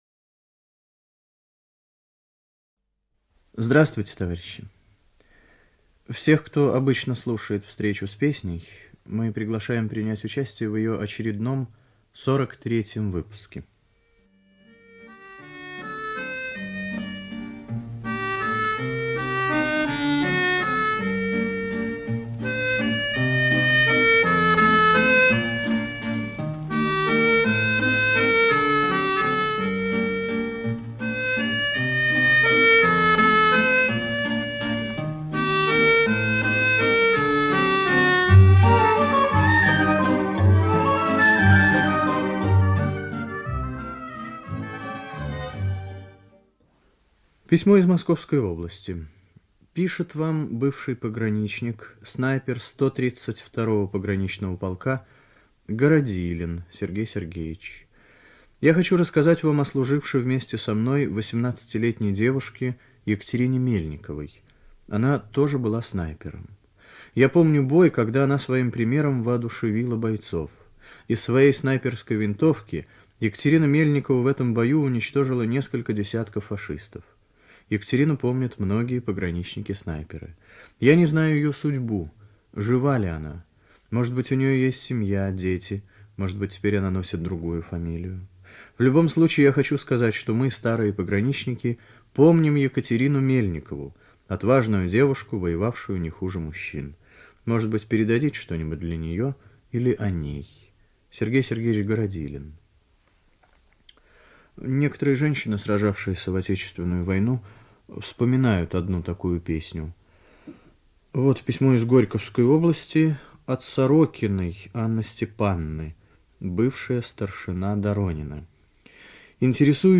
Русская народная песня